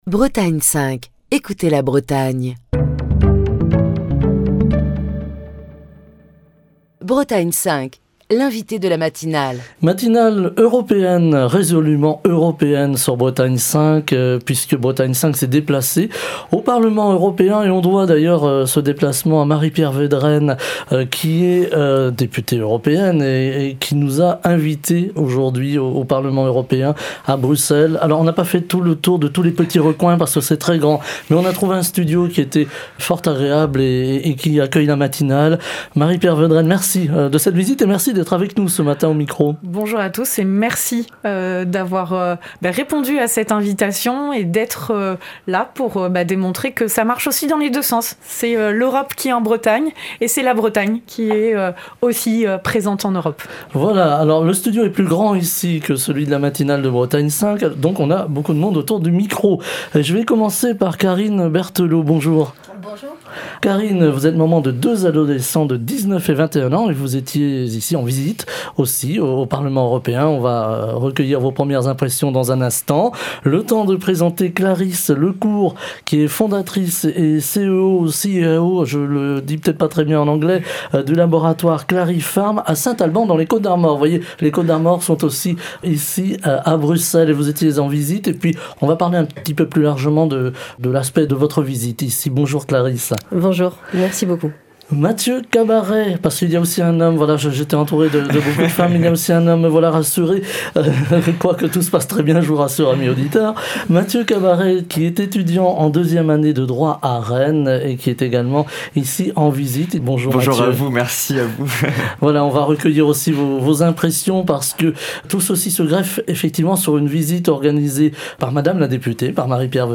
Émission du 2 février 2023. Matinale spéciale Europe depuis le Parlement européen à Bruxelles.